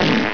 Cute little fart sound, i use it for the imcomming message sound on ICQ!
fart.wav